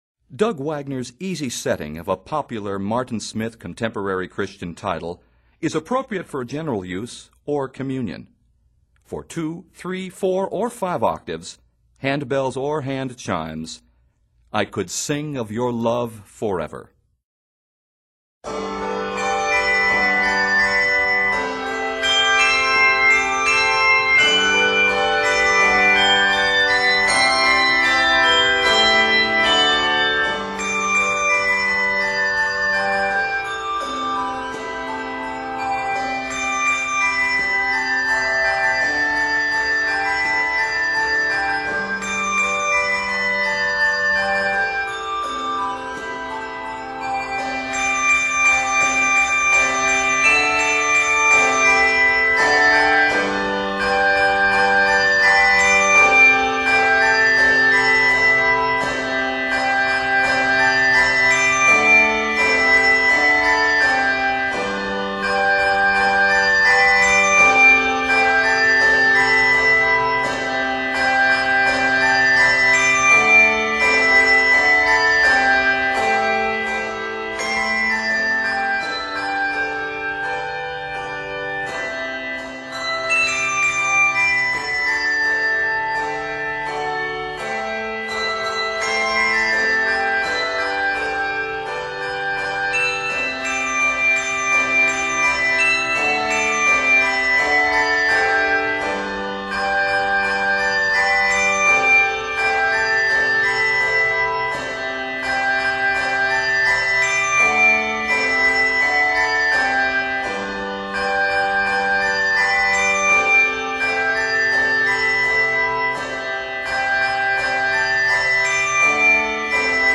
Arranged in G Major and C Major, measures total 102.